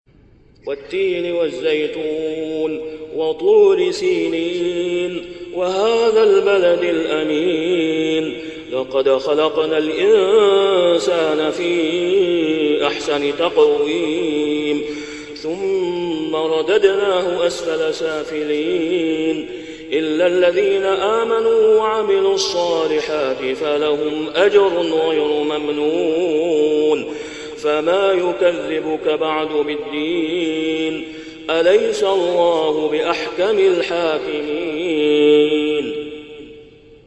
سورة التين > السور المكتملة للشيخ أسامة خياط من الحرم المكي 🕋 > السور المكتملة 🕋 > المزيد - تلاوات الحرمين